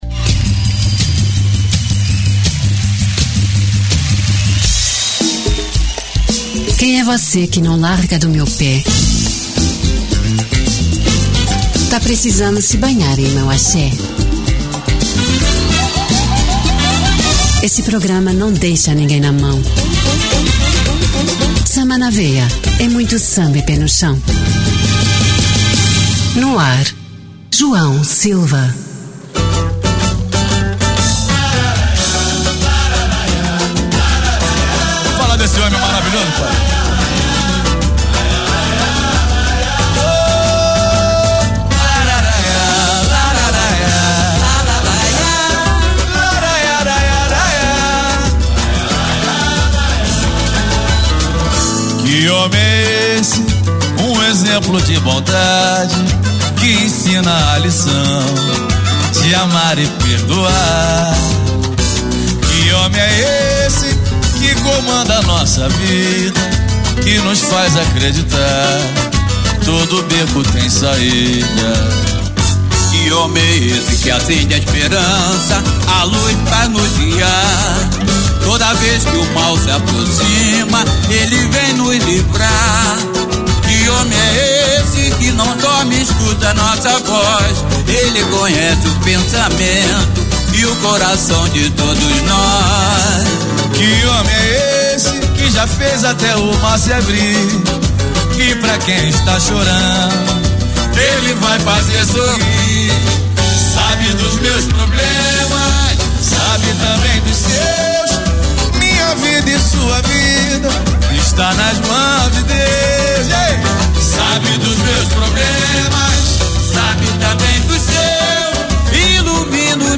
Samba de Raíz